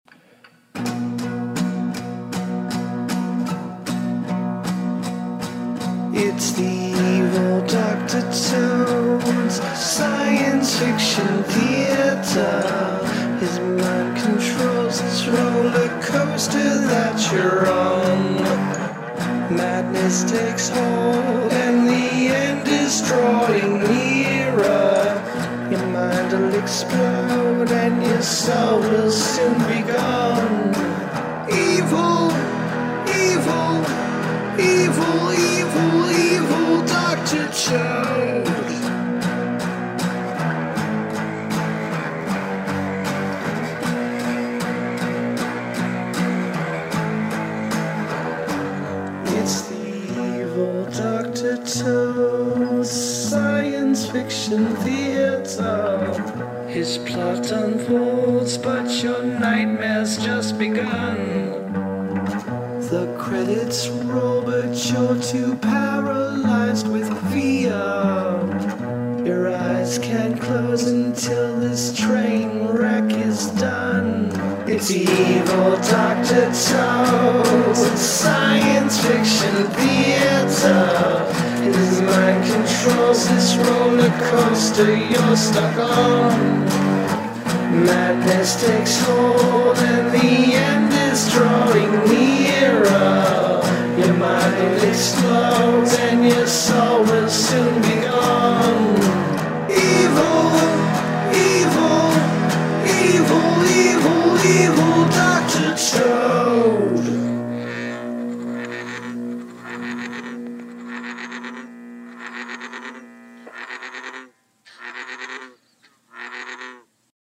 opening theme